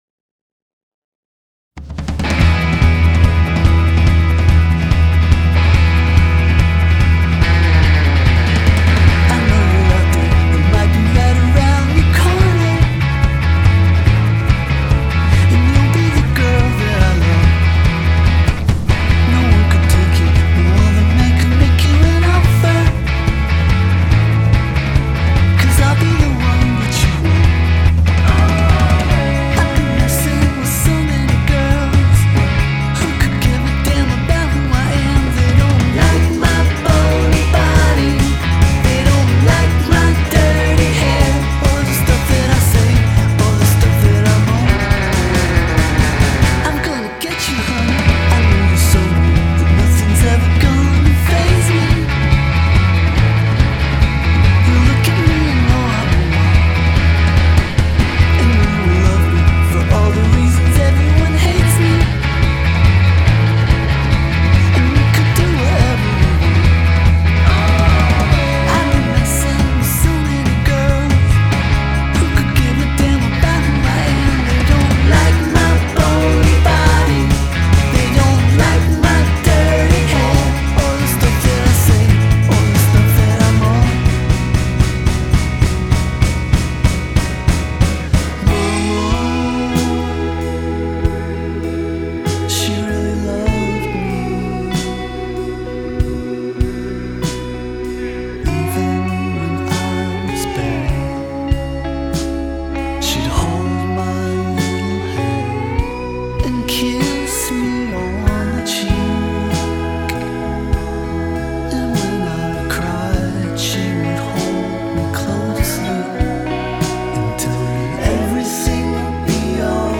Genre: Indie, Rock